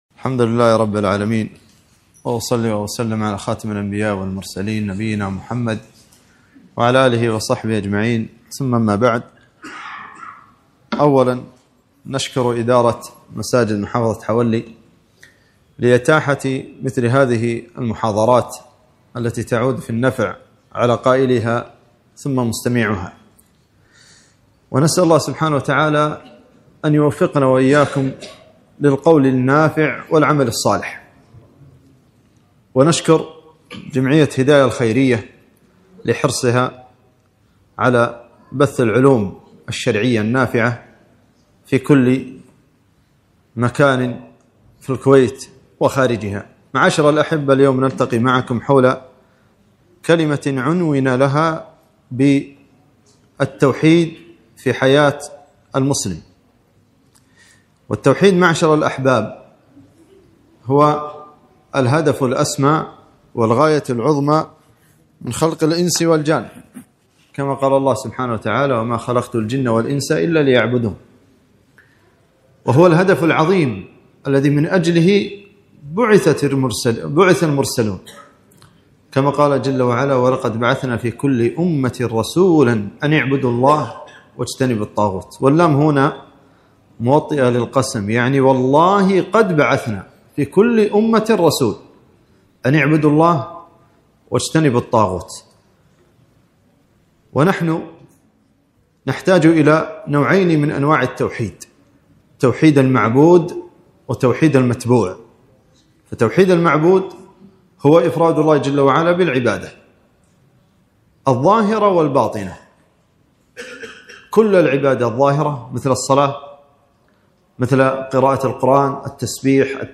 محاضرة - أثر التوحيد في حياة المسلم